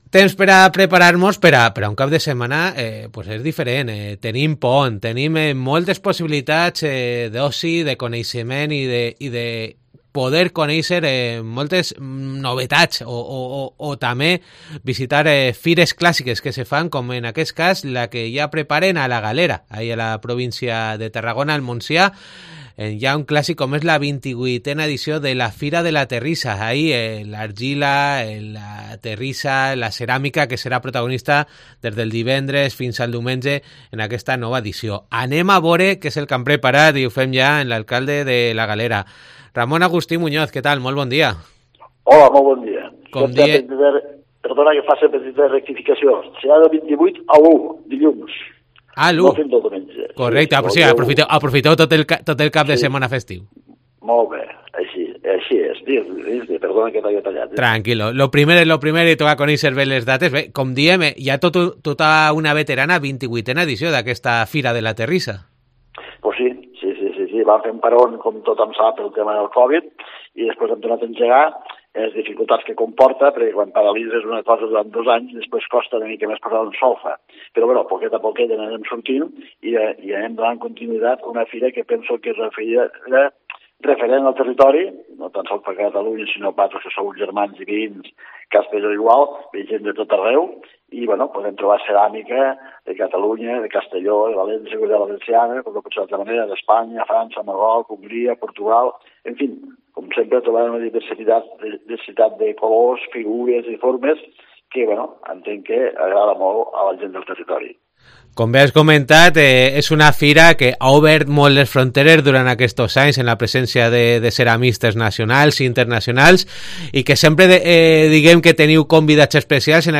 La localitat tarraconenca acollirà ceramistes de llocs nacionals i internacionals, tal com ens ha explicat l'alcalde de La Galera, Ramon Agustí Muñoz.